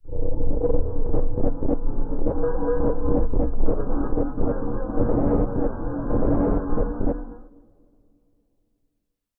Minecraft Version Minecraft Version snapshot Latest Release | Latest Snapshot snapshot / assets / minecraft / sounds / ambient / cave / cave21.ogg Compare With Compare With Latest Release | Latest Snapshot
cave21.ogg